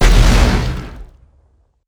Grenade9Short.wav